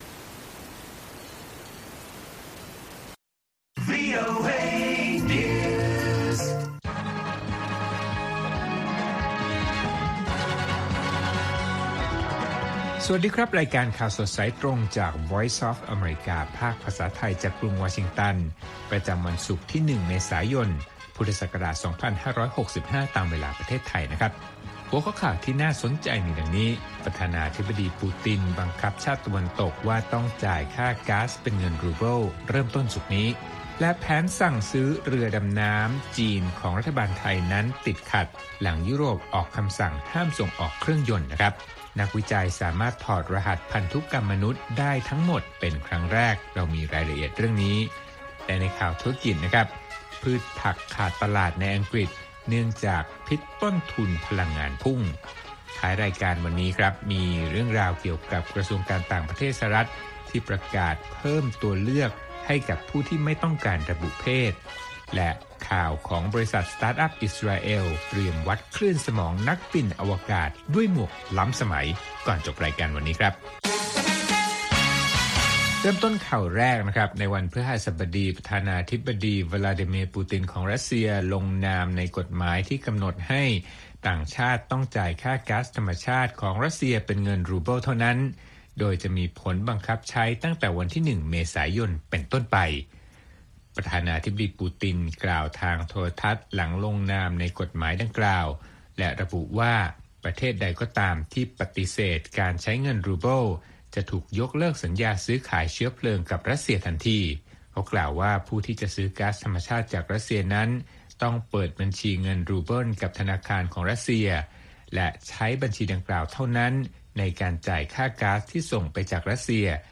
ข่าวสดสายตรงจากวีโอเอ ภาคภาษาไทย ประจำวันศุกร์ที่ 1 เมษายน 2565 ตามเวลาประเทศไทย